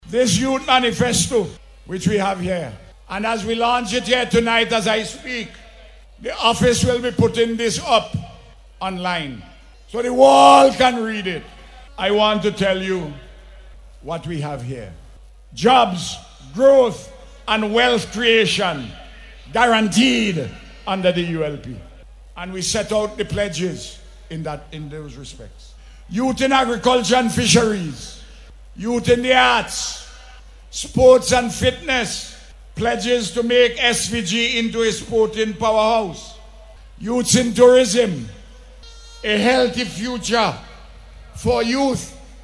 Prime Minister, Dr. Ralph Gonsalves made this statement during last evening’s Youth Rally held to launch the Unity Labour Party’s Youth Manifesto.